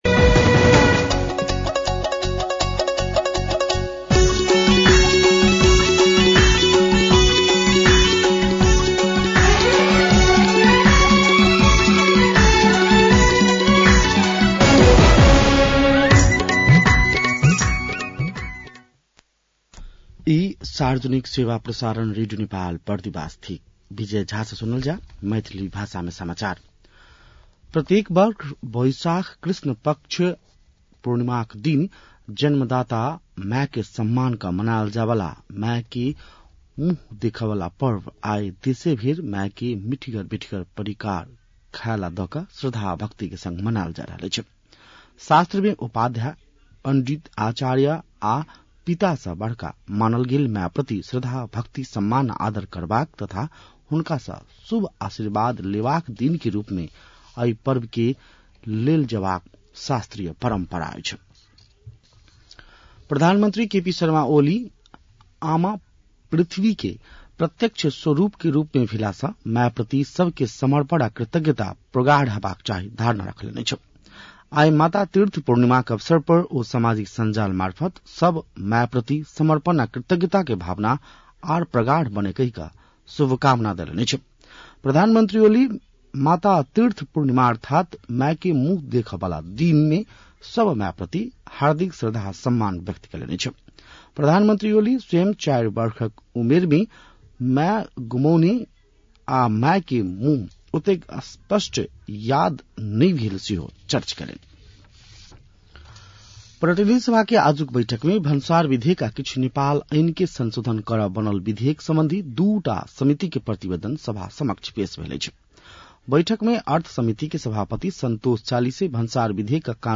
मैथिली भाषामा समाचार : १४ वैशाख , २०८२
6-pm-maithali-news-.mp3